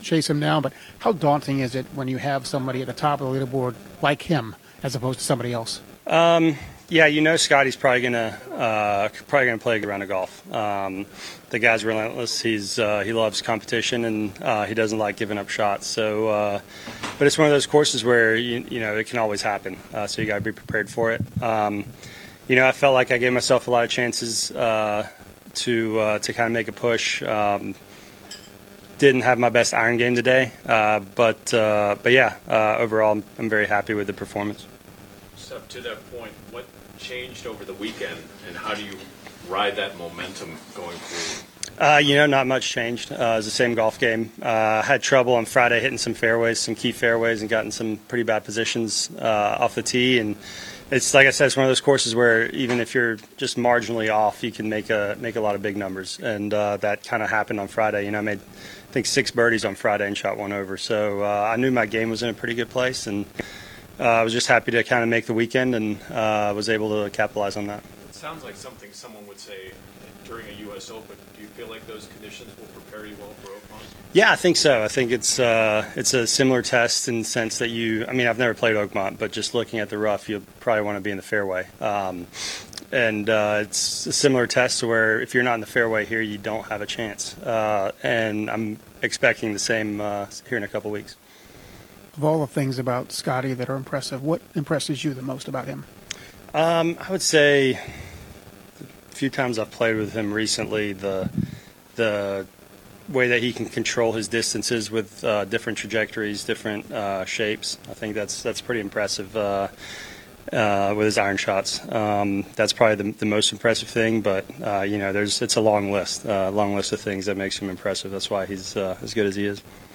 Sepp Straka PGA Tour Post-Match Press Conference after Memorial Tournament Final Round 6 1 2025